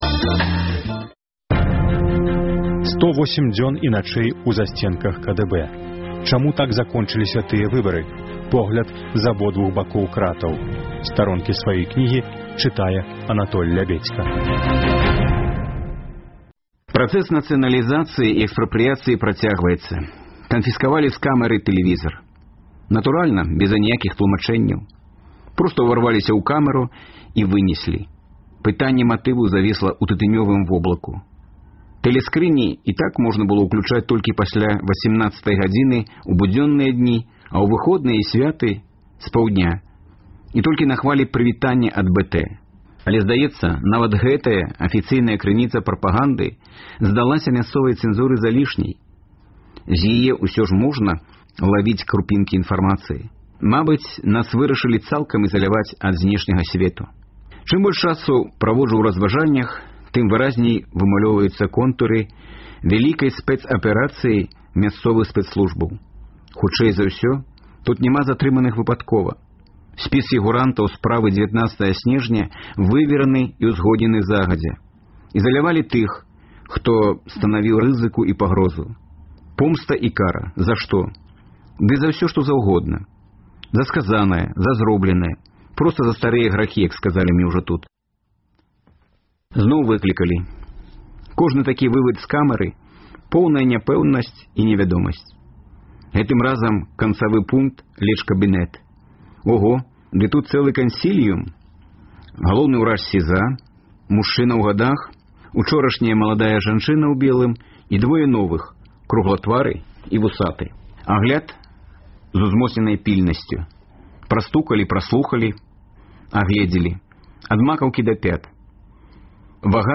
На хвалях Радыё Свабода гучаць разьдзелы кнігі Анатоля Лябедзькі «108 дзён і начэй у засьценках КДБ» у аўтарскім чытаньні.